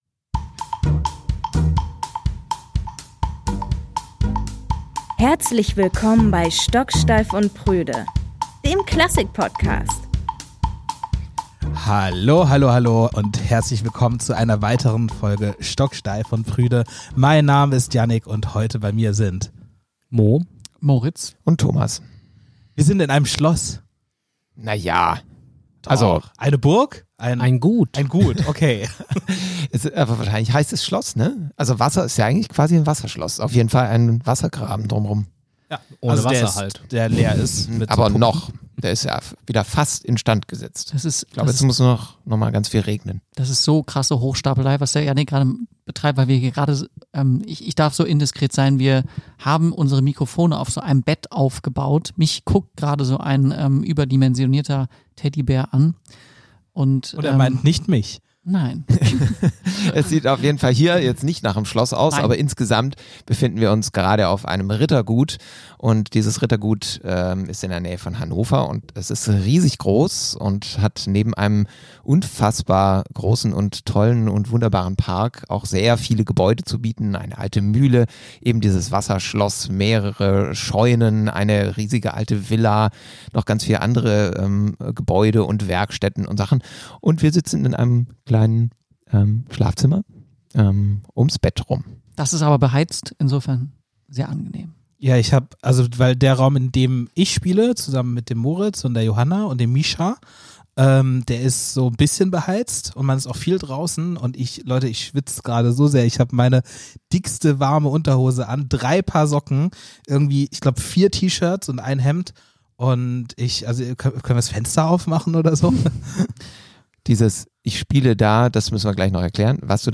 Diese Folge wurde live auf unserem treppenhausfestival aufgezeichnet.
Eine Live-Folge über Musik als soziale Praxis – und über die Frage, wie wir miteinander klingen wollen.